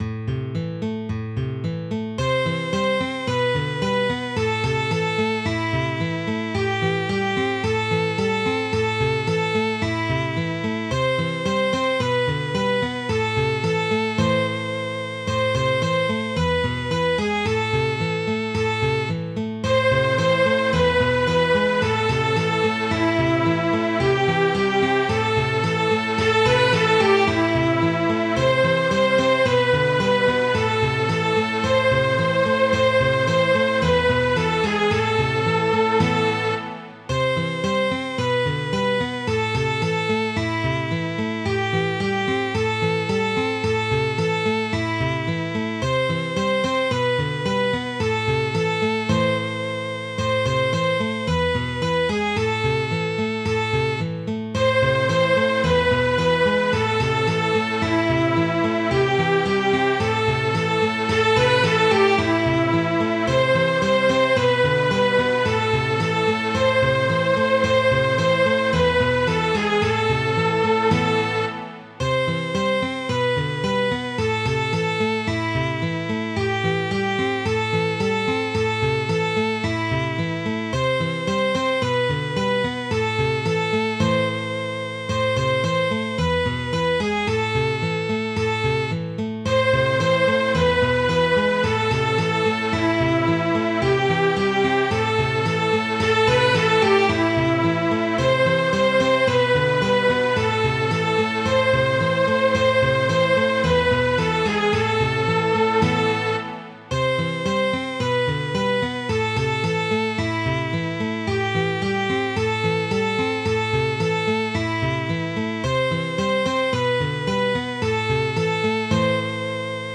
Midi File, Lyrics and Information to Johnny's Gone For a Soldier